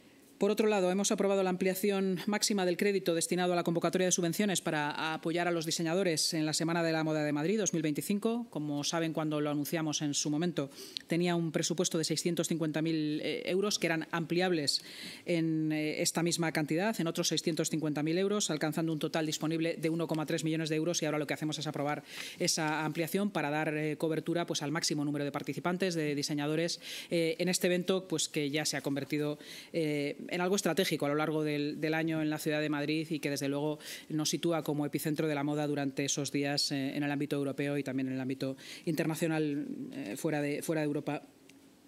Esta duplicación garantiza que el mayor número posible de solicitantes con los requisitos cumplidos pueda acceder a la ayuda, según ha explicado la vicealcaldesa de Madrid y portavoz municipal, Inma Sanz.